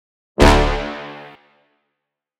Download Stab sound effect for free.
Stab